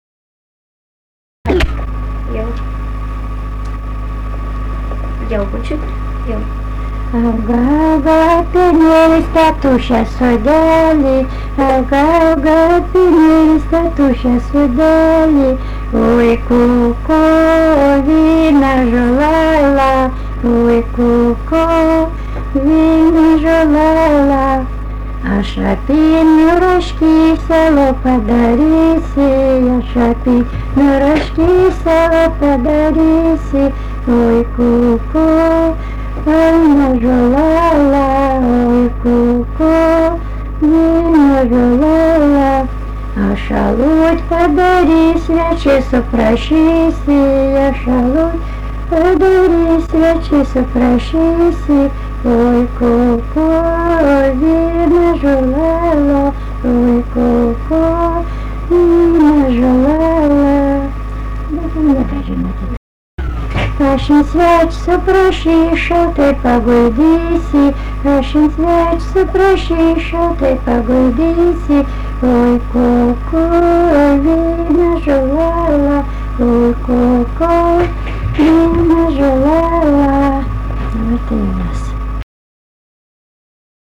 daina
Jurgėnai
vokalinis